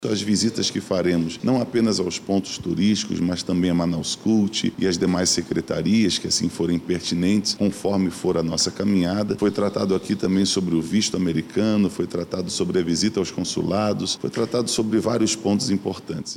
Como resultado do encontro, foi acordado com os parlamentares, uma série de visitas aos pontos turísticos da cidade e consulados, conforme explica o presidente da Comissão, vereador João Carlos, do Republicanos.